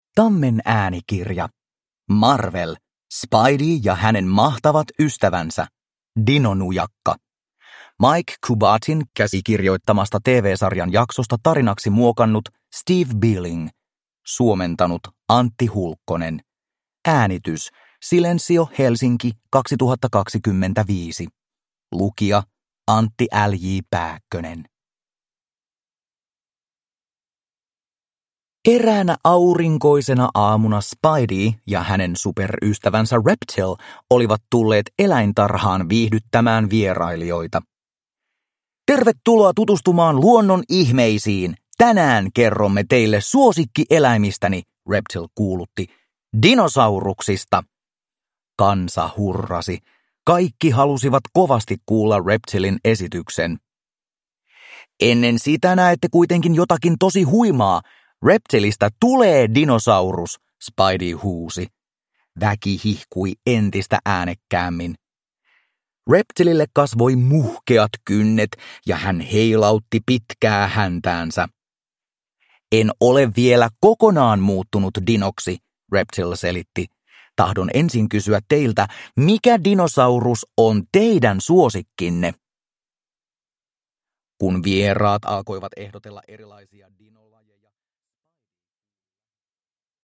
Marvel. Spidey ja hänen mahtavat ystävänsä. Dinonujakka – Ljudbok